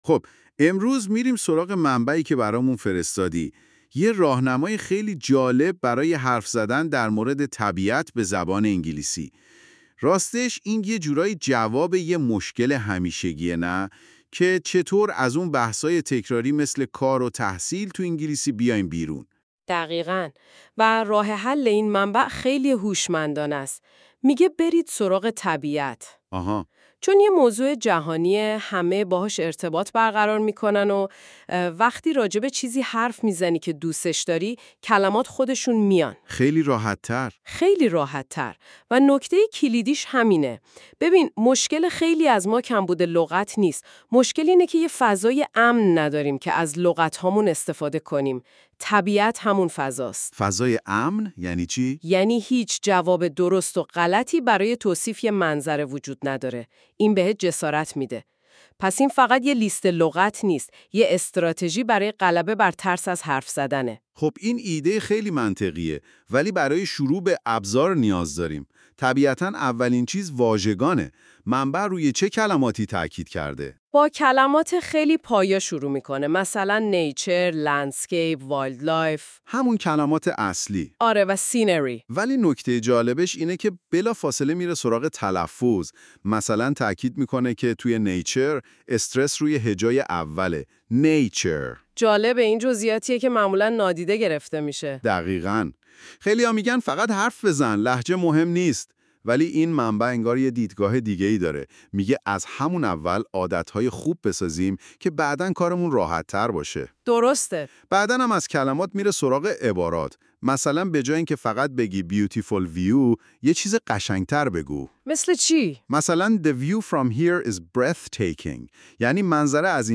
english-conversation-about-nature.mp3